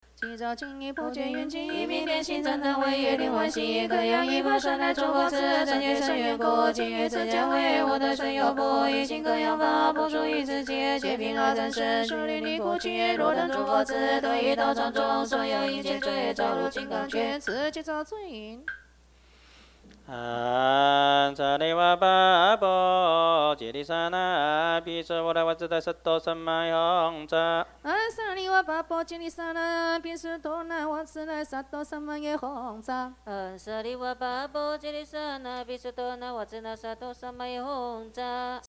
佛教音樂  Mp3音樂免費下載 Mp3 Free Download